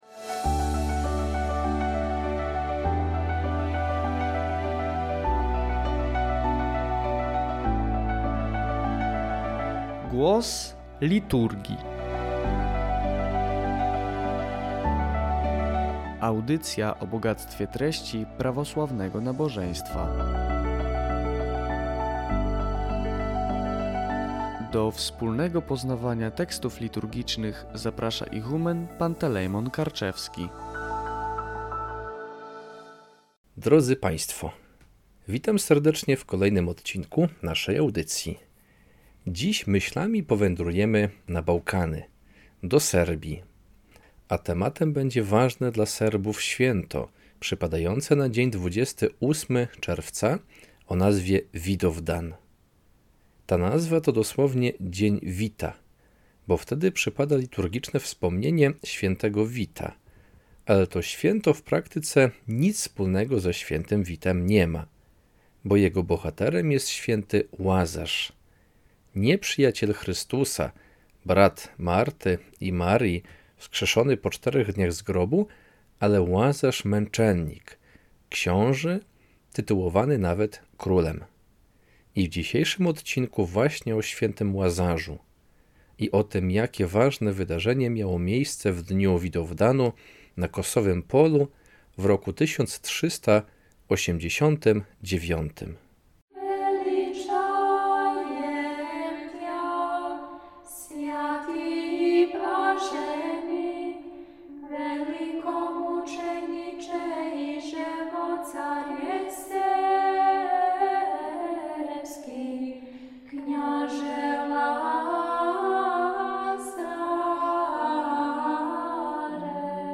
Audycja „Głos Liturgii” co dwa tygodnie.